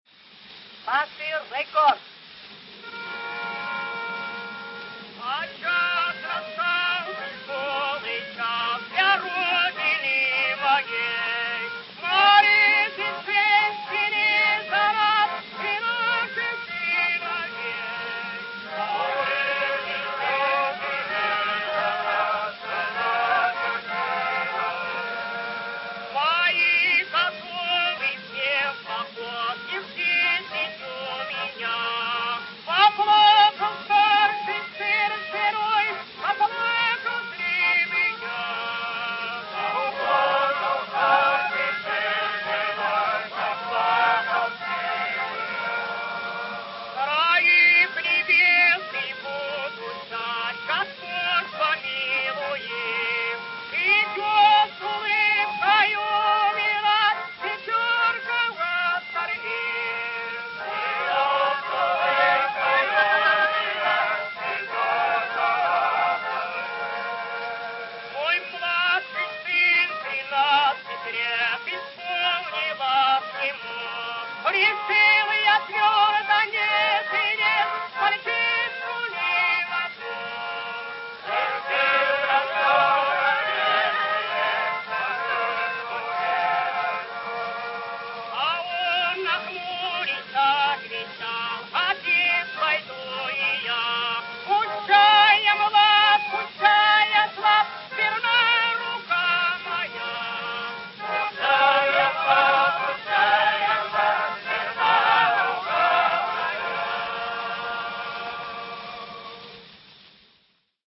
Грампластинка Pathe 27921 «Трансвааль».
Поёт русский хор